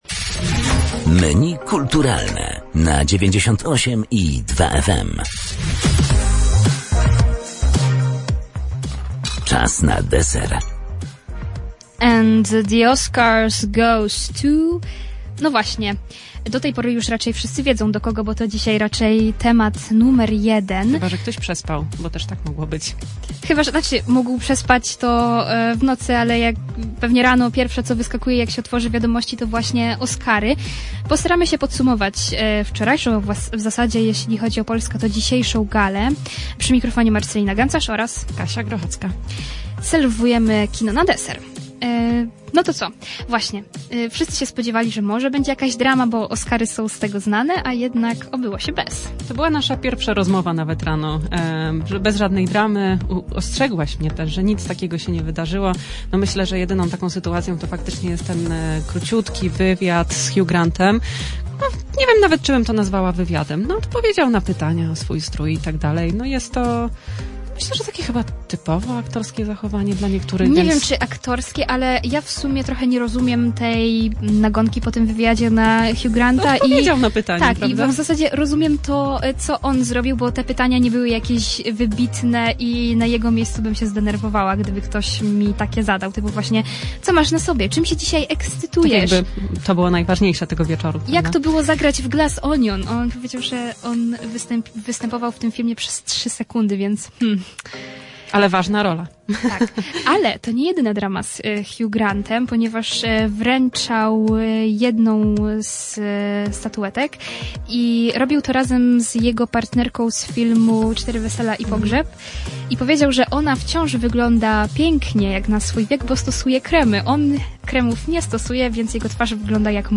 nasze redaktorki